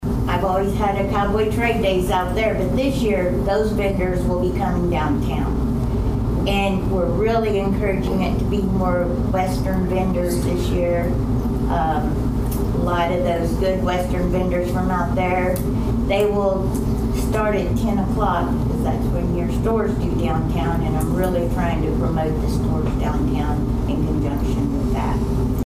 The Dewey City Council met for the first time in the month of September on Tuesday night at Dewey City Hall.